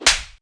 HARDSLAP.mp3